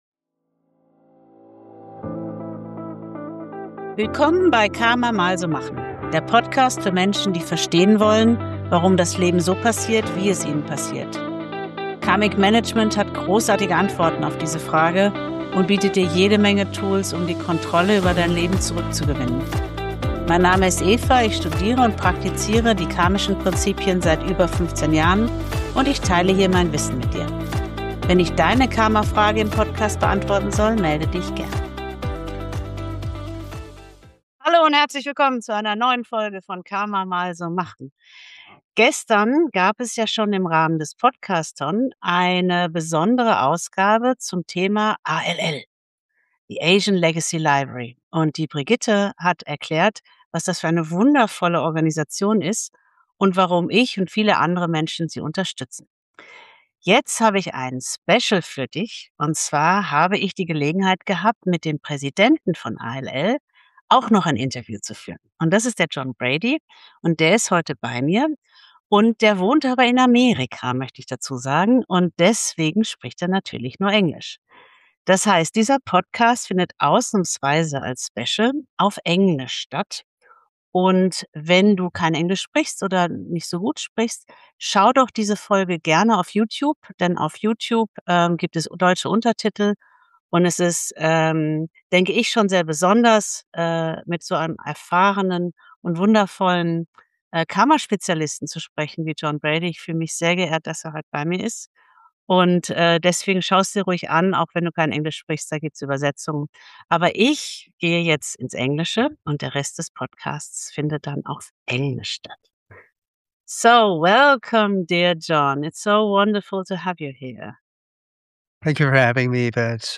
Folge 077 - Interview mit